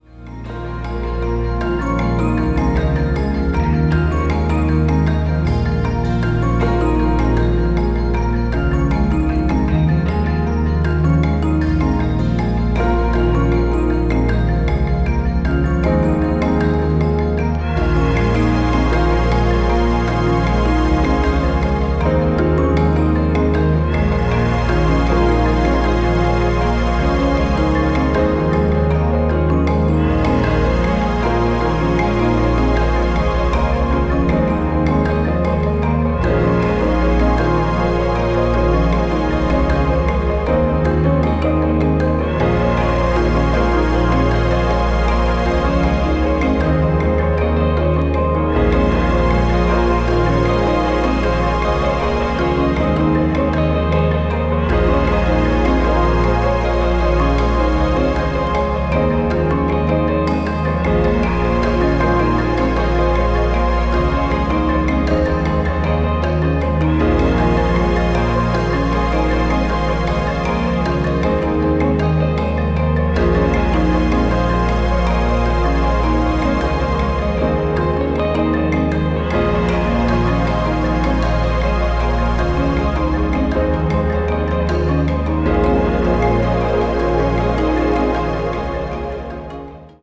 ambient   electronic   meditation   new age   synthesizer